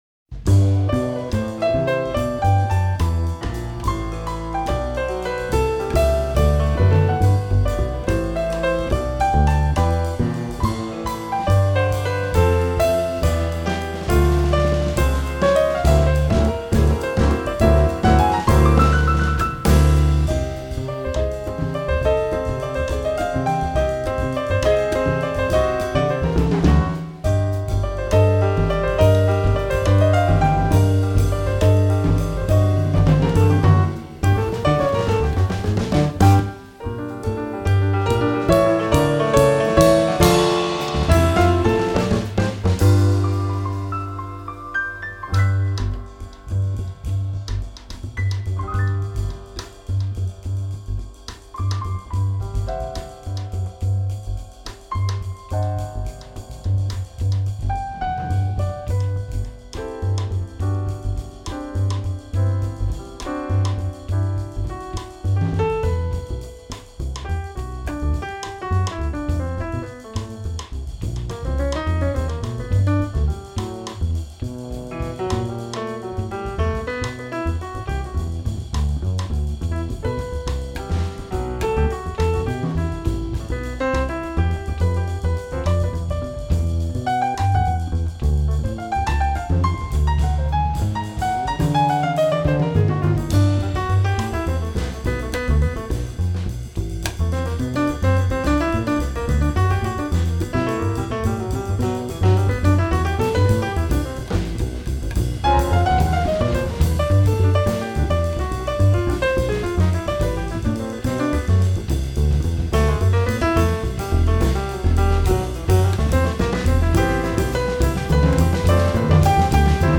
Contrebasse
Batterie
Piano